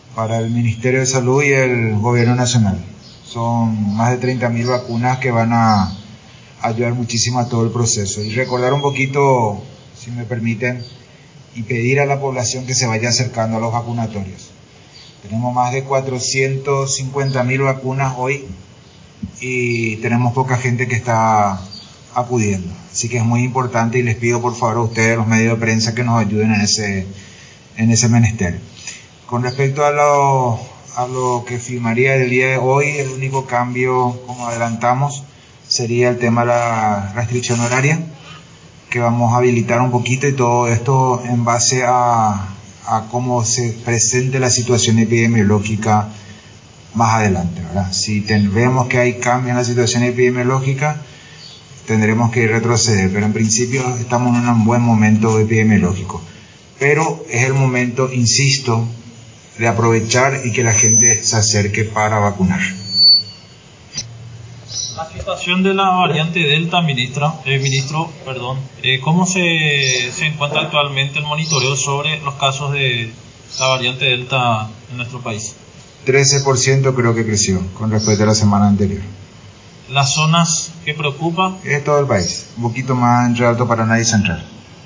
Así lo expuso este lunes por Borba durante su visita a Palacio de Gobierno, donde se anunció una donación de 30.000 dosis de la vacunas anticovid por parte de la CONMEBOL mediante un acuerdo con la empresa china Sinovac.
En la ocasión, el titular de Salud fue abordado por los trabajadores de prensa acerca de las novedades en el nuevo decreto de medidas sanitarias que será socializado en el transcurso de las próximas horas.